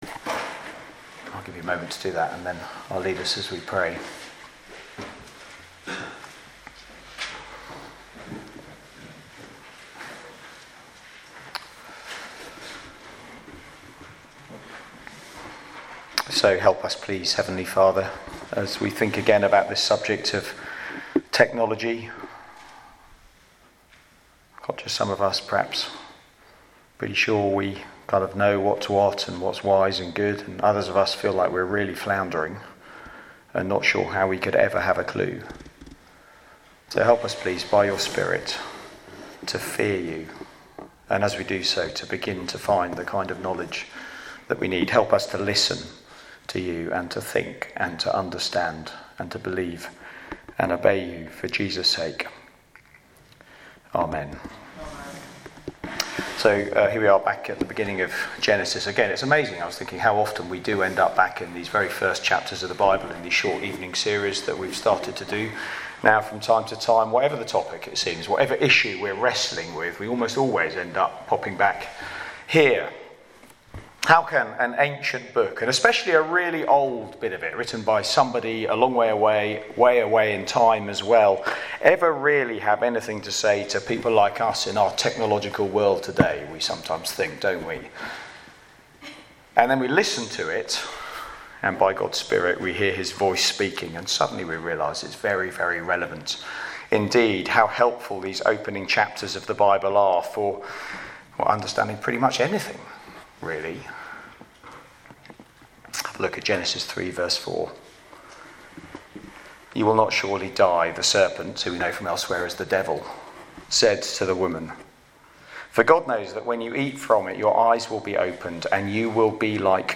Media for Sunday Evening on Sun 19th Nov 2023 18:00
Being like God? Sermon All music is licensed by Podcast/RSS FEED The media library is also available as a feed, allow sermons to be automatically downloaded to your PC or smartphone.